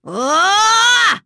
Nicky-Vox_Casting4.wav